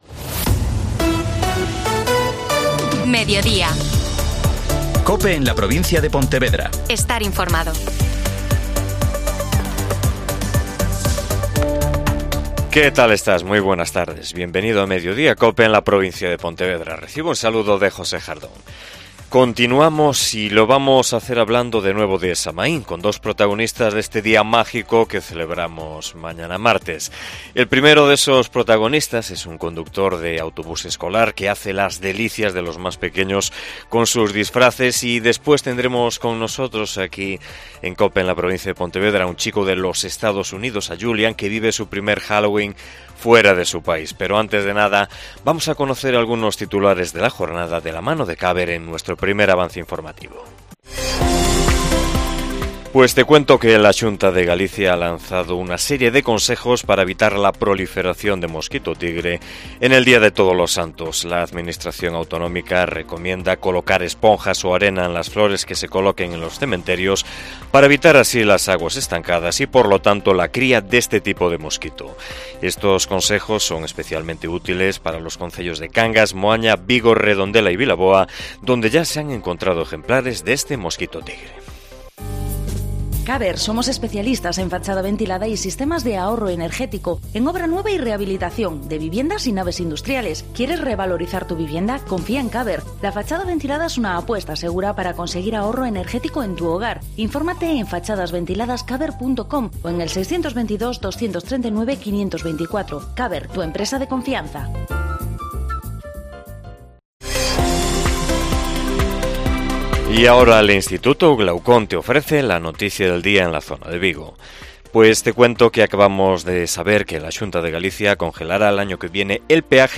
AUDIO: Magazine provincial